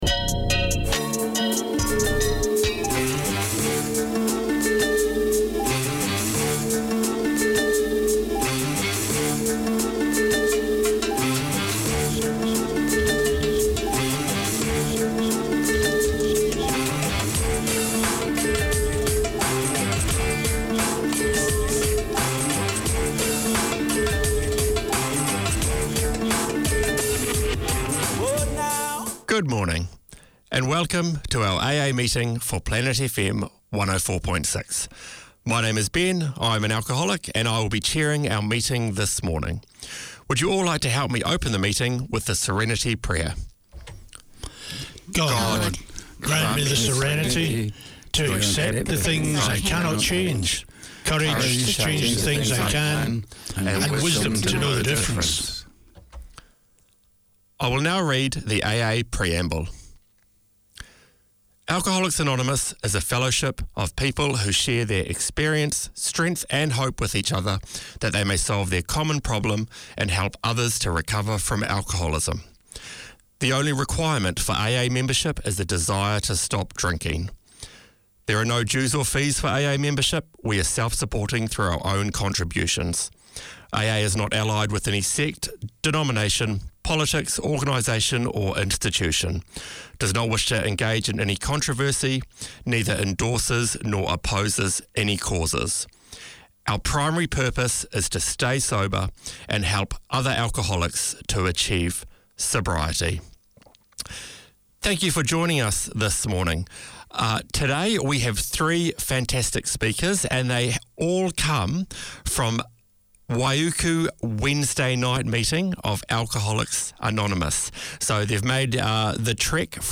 Hear guests on current topics, who speak candidly about what's good and what's not good for their mental health.